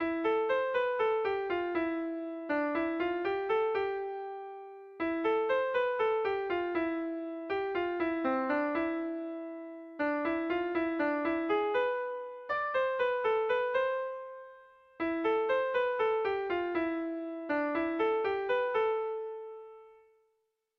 A1A2BA3